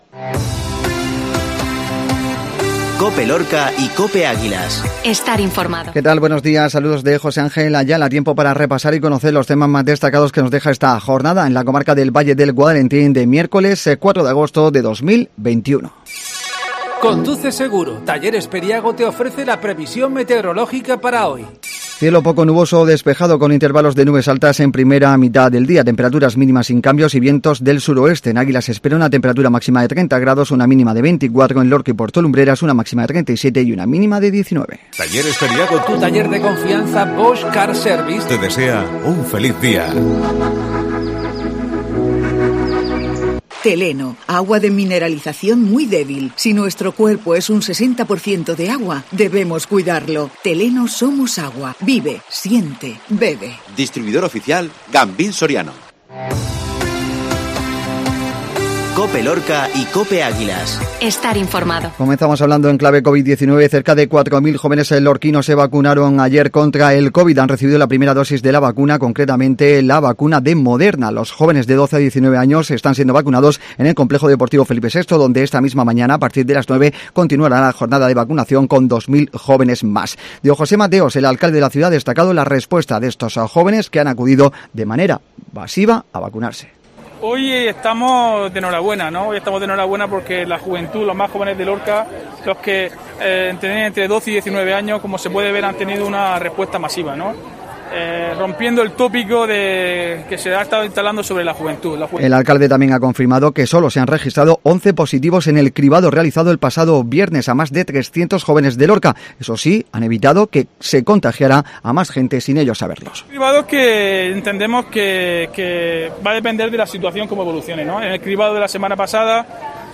INFORMATIVO MATINAL MIERCOLES